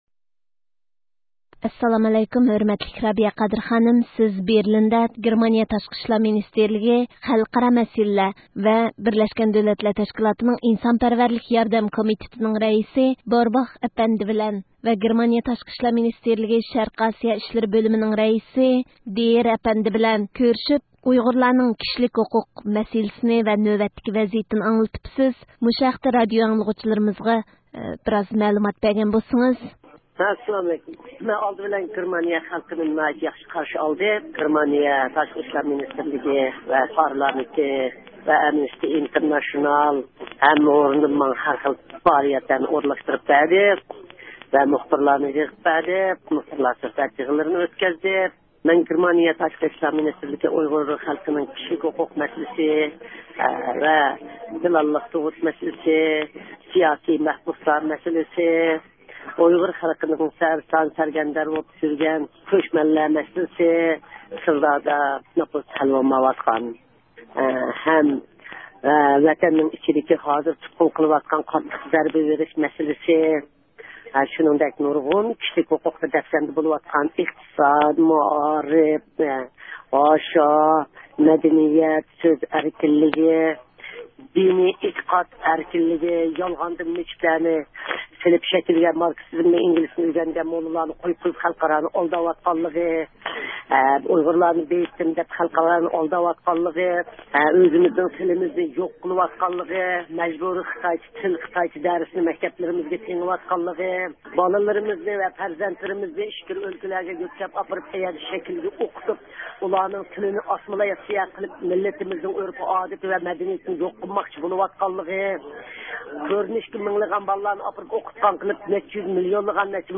رابىيە خانىم بىلەن ئۆتكۈزگەن سۆھبىتىدىن ئاڭلاڭ.